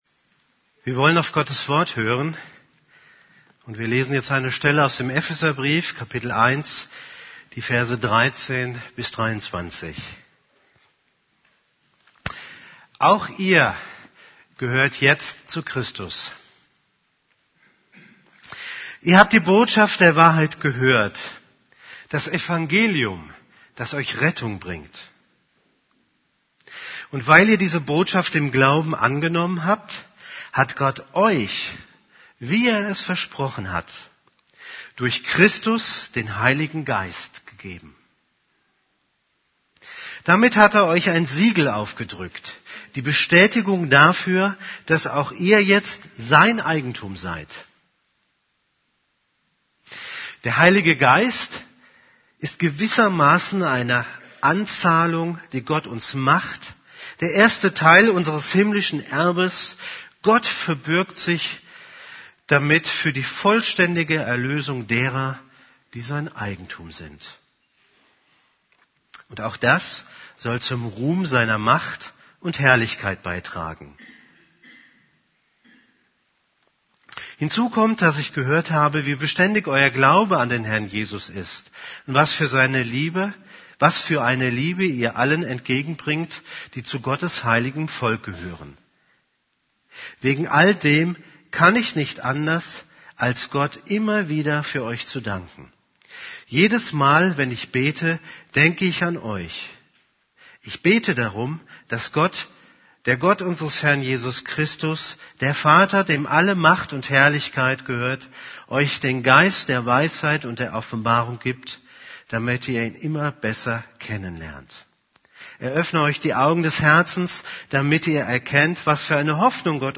> Übersicht Predigten Der Geist Gottes in unserem Leben Predigt vom 23.